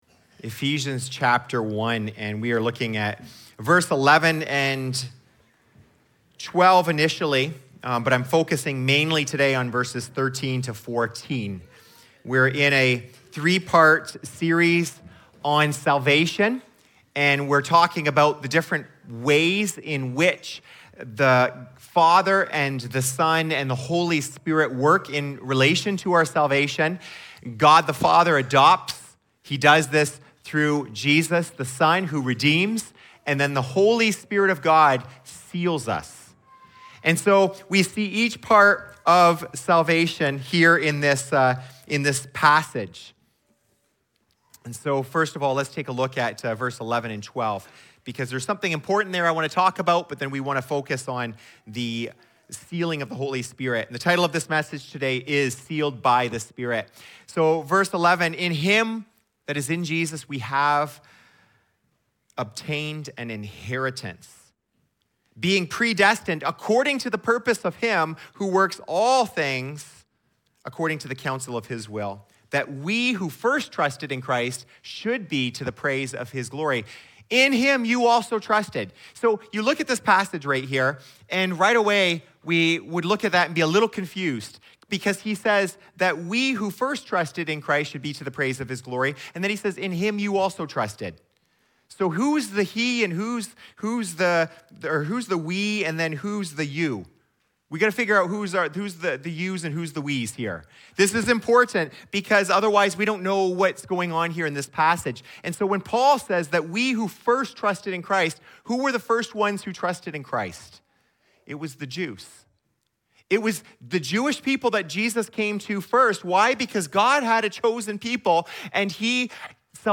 A message from the series "Ephesians." This week, we’re digging into the incredible truths found in Ephesians 1 Vs 19-21, unpacking what it means to live in the exceeding greatness of God’s power. We’ll explore how Paul defines, demonstrates, and directs this power toward us who believe.